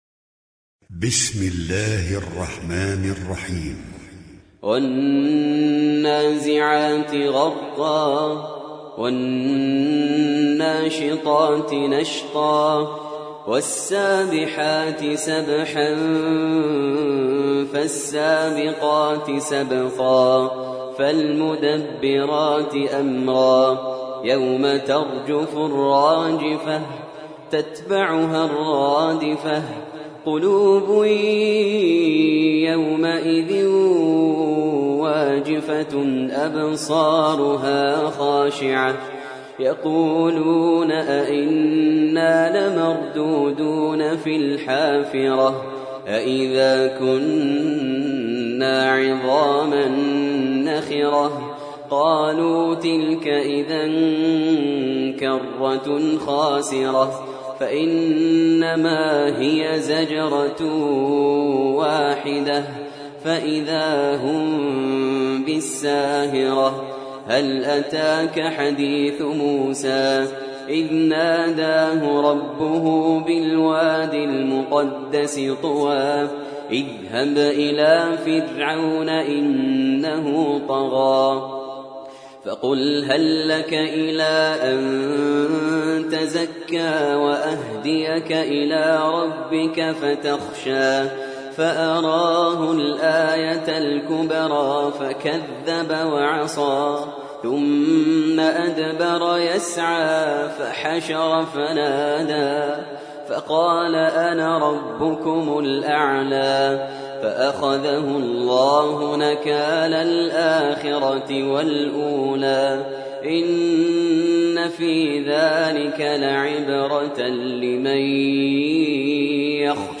سورة النازعات - المصحف المرتل (برواية حفص عن عاصم)
جودة عالية